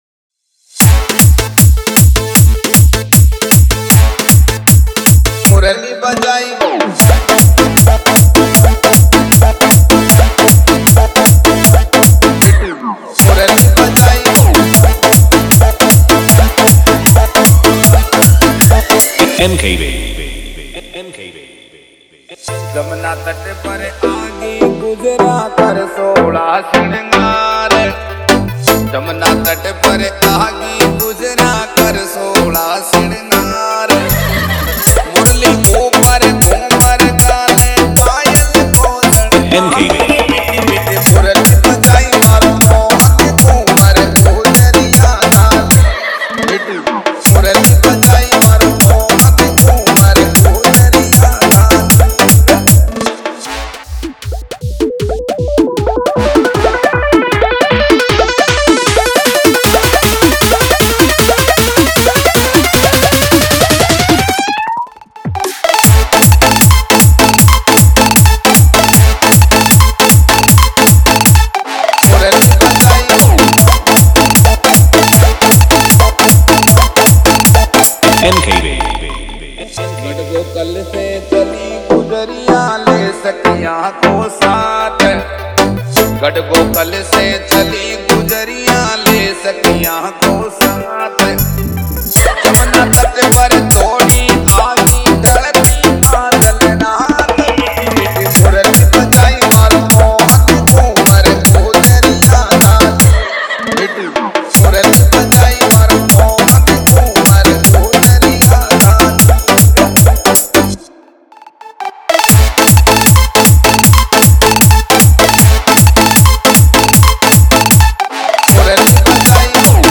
Krishna bhakti dj mix, Murali bhajan dj mix